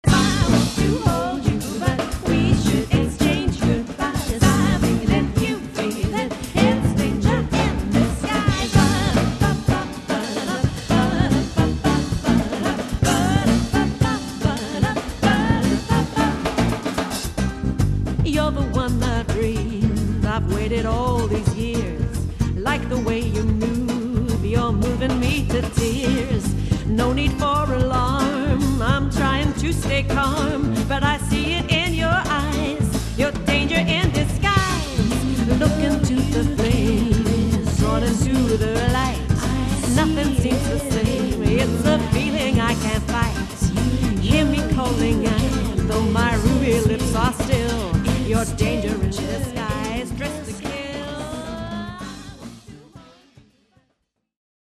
jazz singer
flamenco guitar duo
saxophonist/flautist
Consisting of a core ensemble of voice and two guitars
Australian, Jazz, Vocal